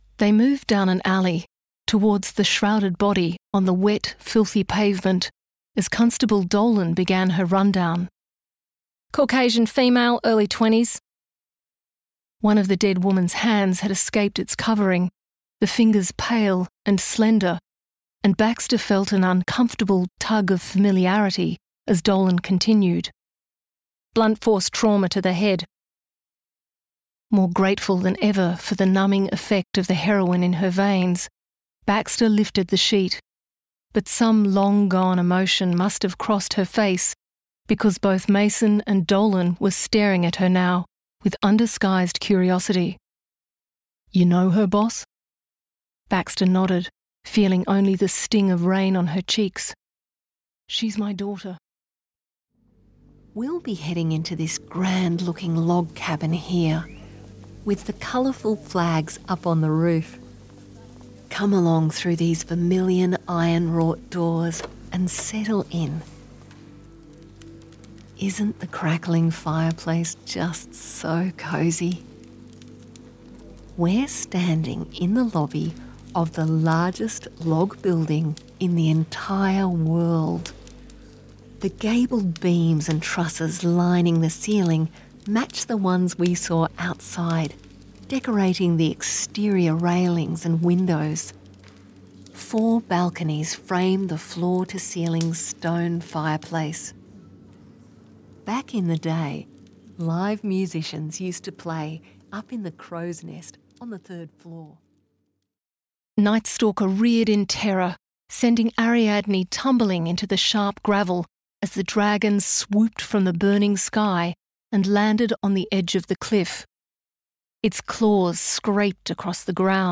0717Audiobook_reel.mp3